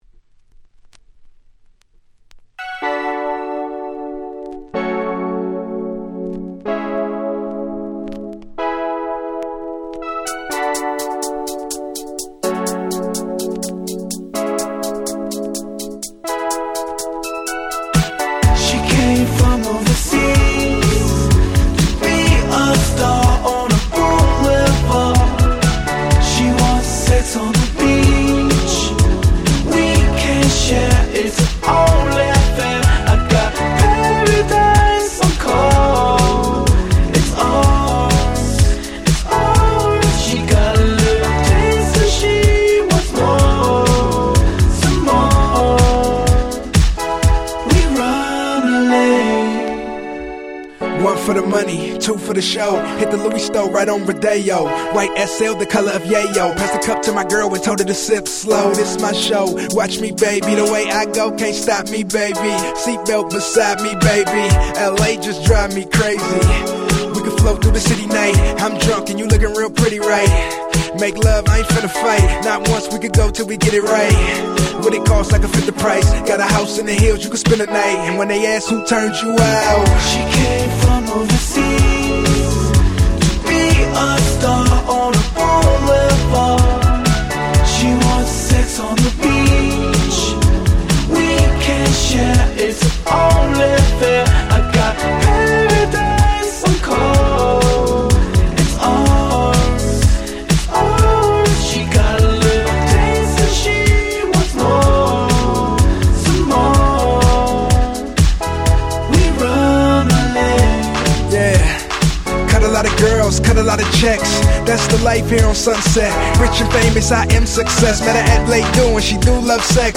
09' Very Nice West Coast Hip Hop !!
キャッチーで軽快なBeatな超良い曲！！